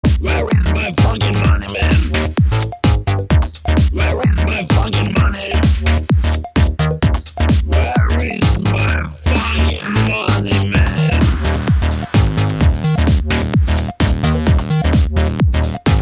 electrohaus/electroclash